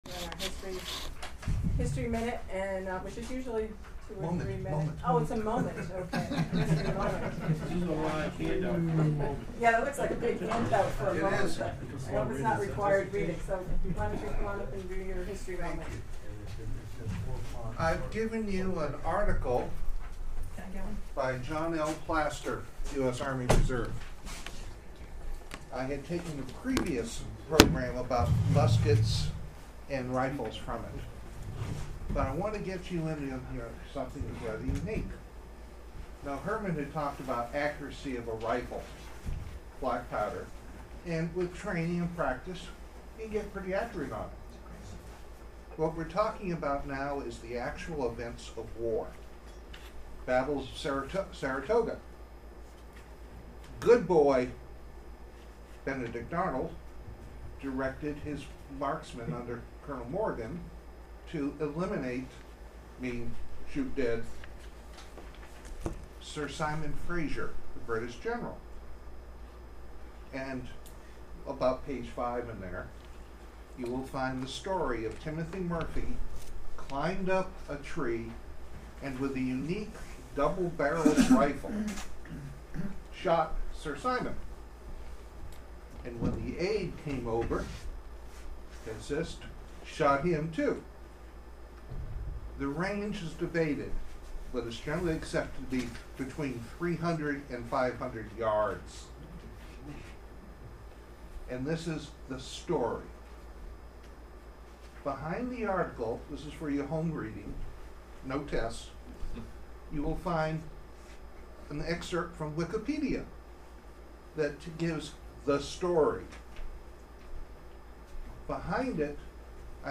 Recorded on February 2nd, 2010 at Bemis Hall in Lincoln, Massachusetts